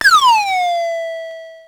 Index of /90_sSampleCDs/300 Drum Machines/Electro-Harmonix Spacedrum
Drum04.wav